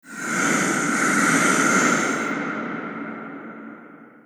Efecto de respiración fuerte de un marciano
respiración
Sonidos: Especiales